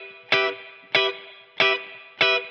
DD_TeleChop_95-Emin.wav